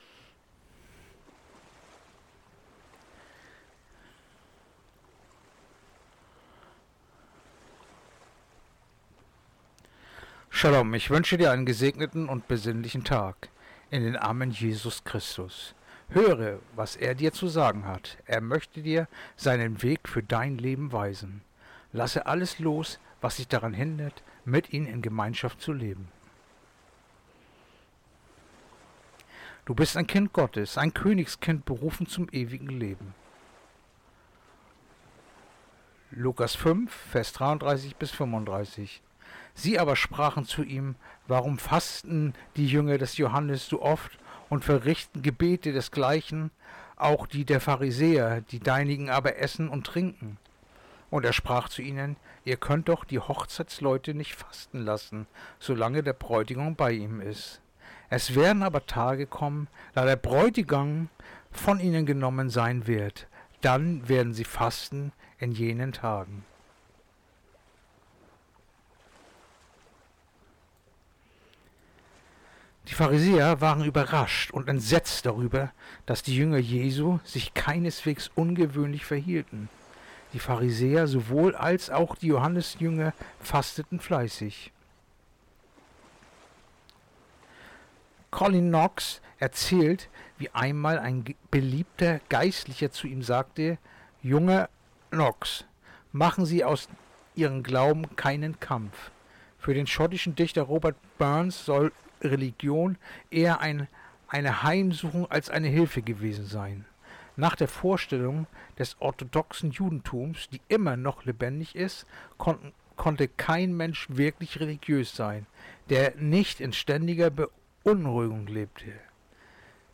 Andacht-vom-18.-Januar-Lukas-5-33-35